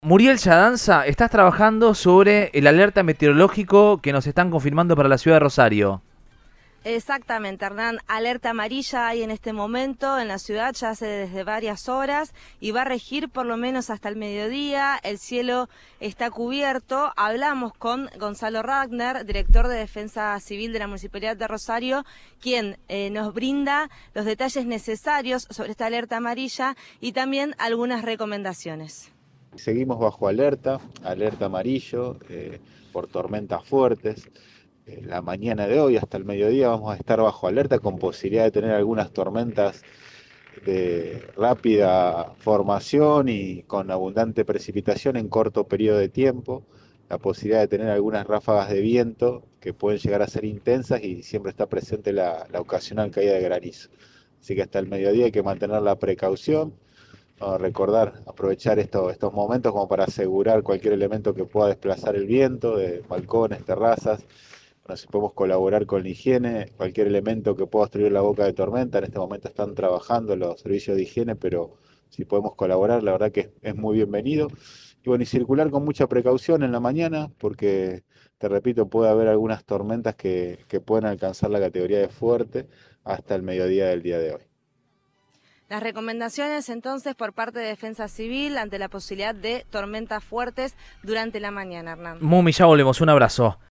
Lo confirmó a Cadena 3 Rosario el titular del área de Defensa Civil, Gonzalo Ratner.